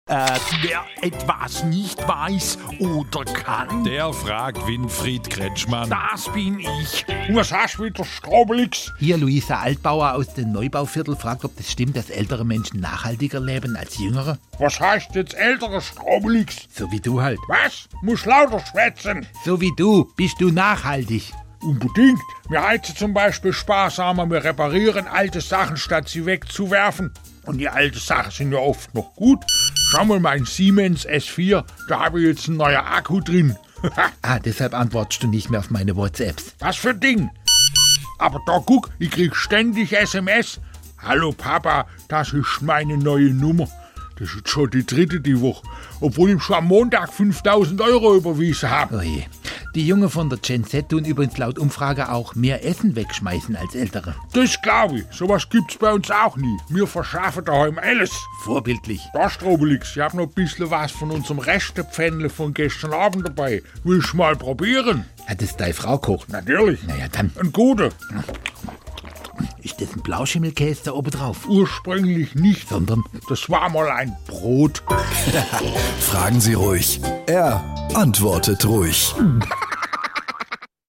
SWR3 Comedy Fragen Sie Kretschmann: Nachhaltigkeit bei Älteren